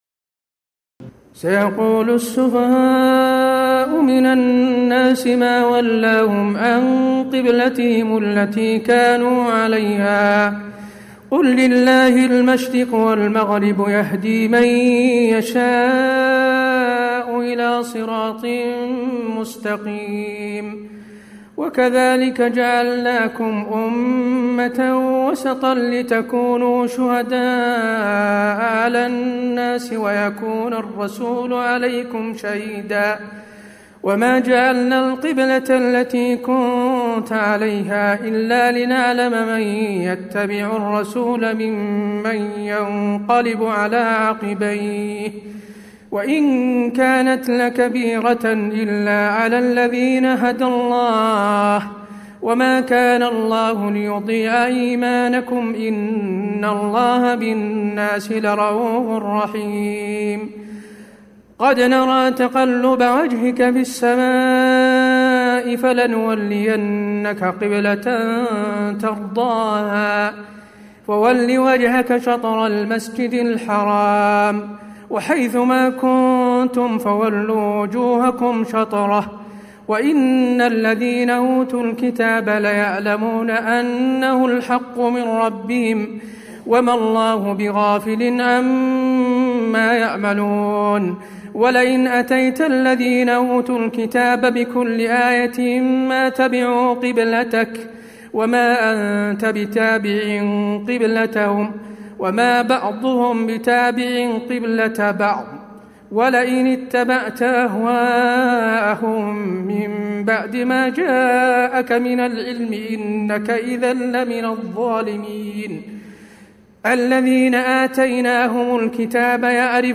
تراويح الليلة الثانية رمضان 1436هـ من سورة البقرة (142-203) Taraweeh 2 st night Ramadan 1436 H from Surah Al-Baqara > تراويح الحرم النبوي عام 1436 🕌 > التراويح - تلاوات الحرمين